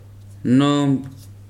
[nóòmp] n. sago